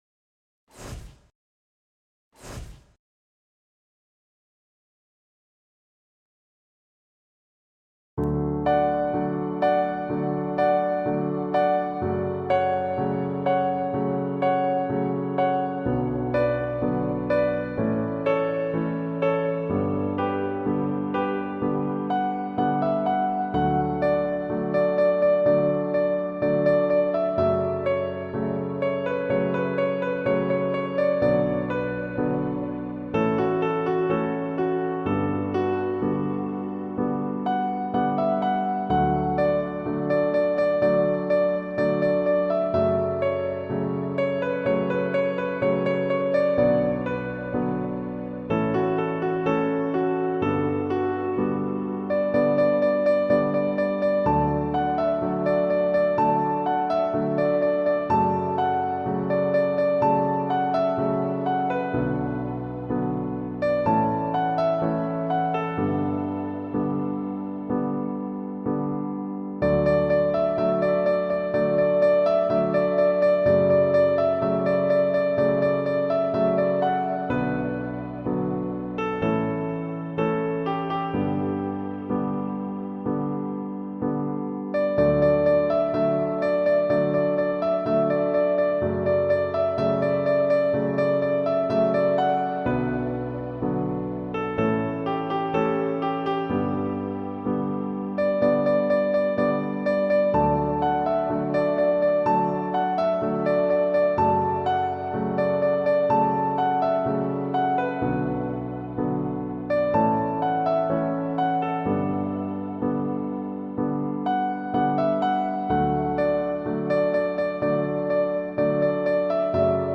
The images, message and music all seem to compete with each other but in actuality they don’t. Well done Maestro.